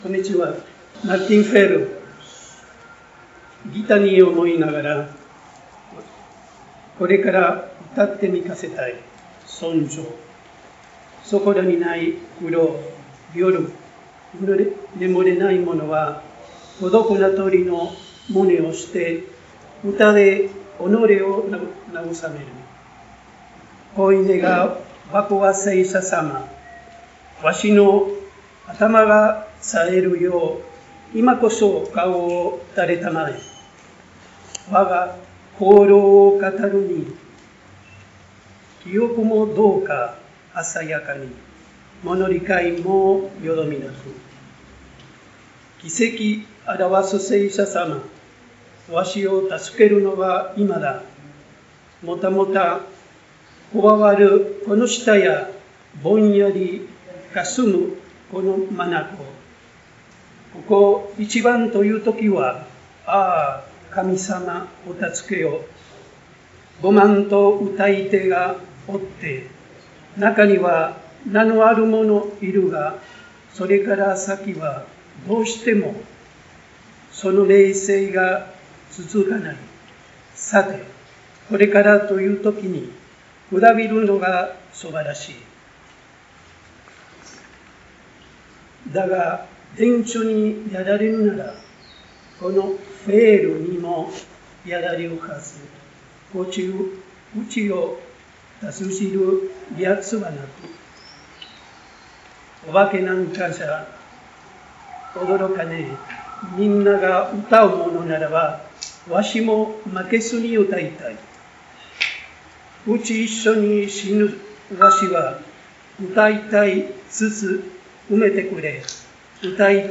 Evento: Jornadas Políglotas Martín Fierro (City Bell, 1° de diciembre de 2023)